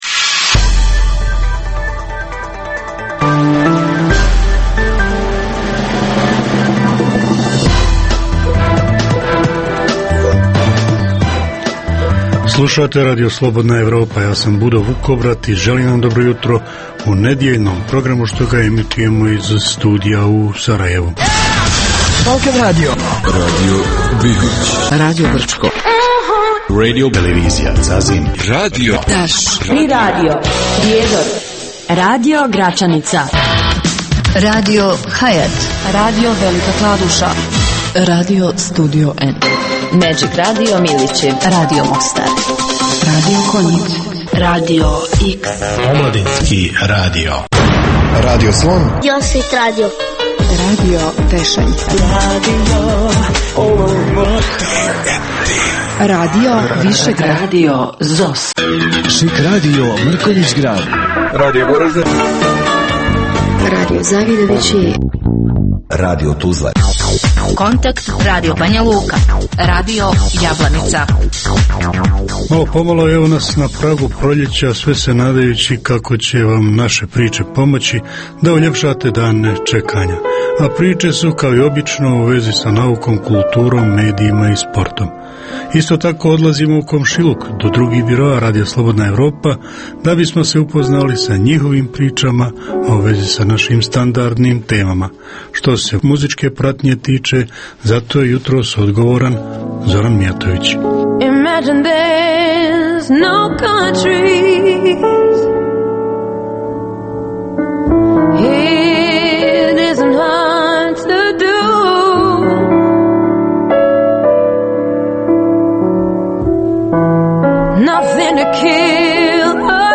Jutarnji program namijenjen slušaocima u Bosni i Hercegovini. Kao i obično, uz vijesti i muziku, poslušajte pregled novosti iz nauke i tehnike, te čujte šta su nam pripremili novinari RSE iz Zagreba i Beograda